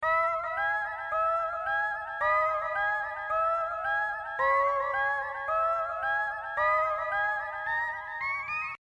strange-noise_14282.mp3